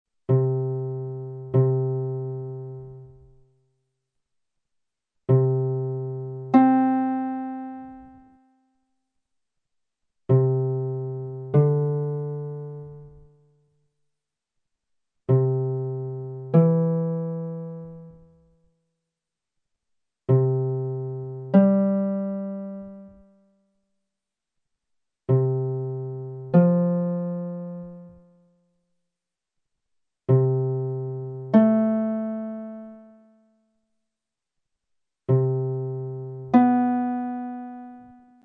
Prova a riconoscere gli intervalli, completando gli spazi (scrivi: UG, 2M, 3M, 4G, 5G, 6M, 7M, 8G)